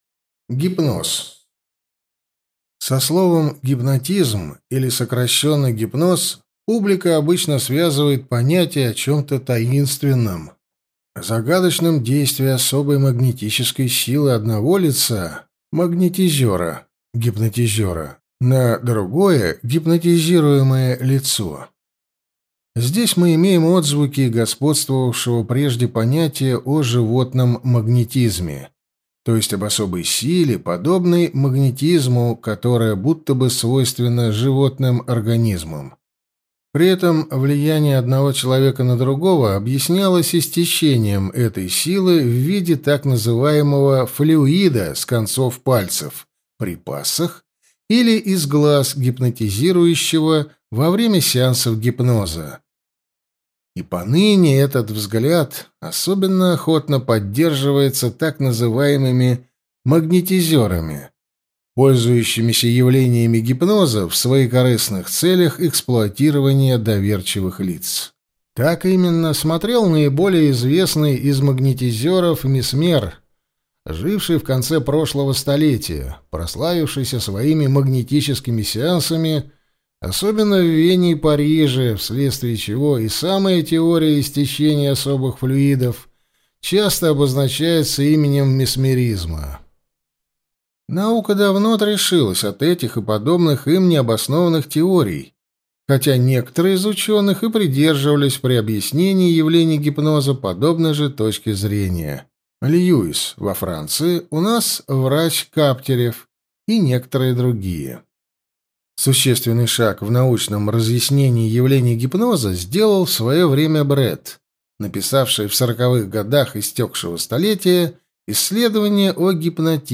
Аудиокнига Гипноз. Внушение. Телепатия | Библиотека аудиокниг